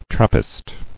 [Trap·pist]